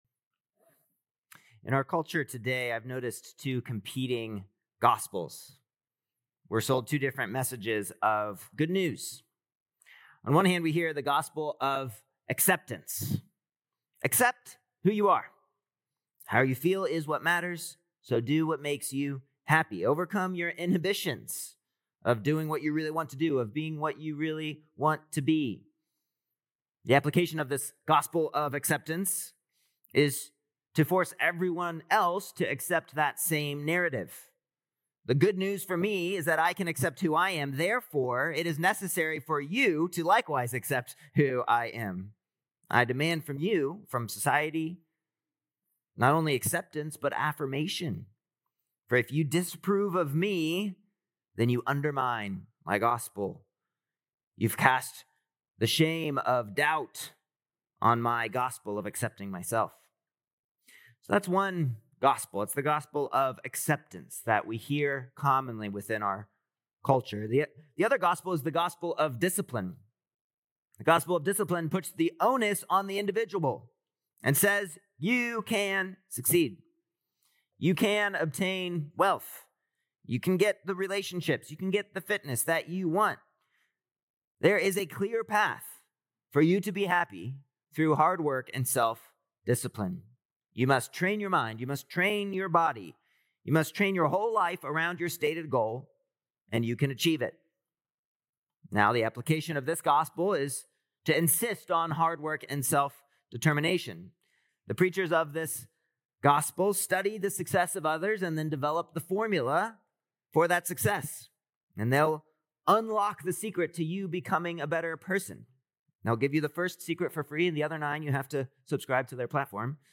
Mar 22nd Sermon